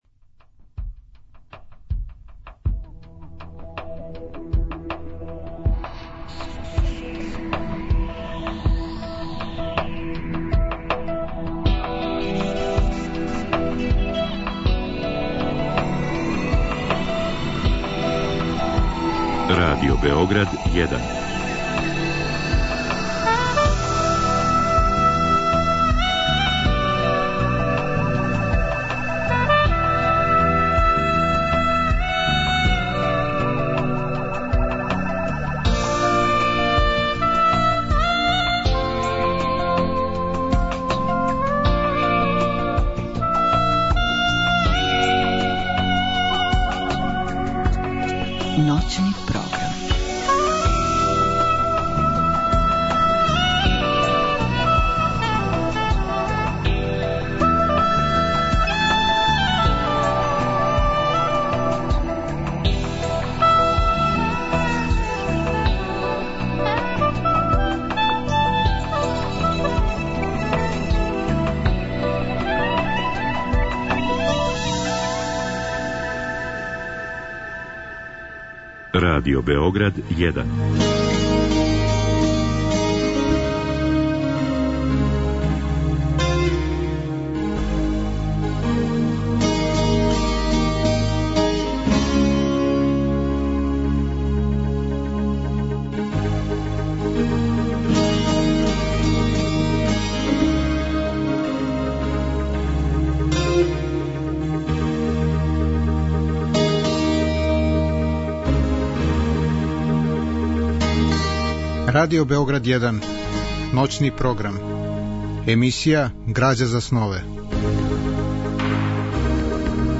Разговор и добра музика требало би да кроз ову емисију и сами постану грађа за снове.
Ова радио-драма реализована је 1971. године у продукцији Драмског програма Радио Београда.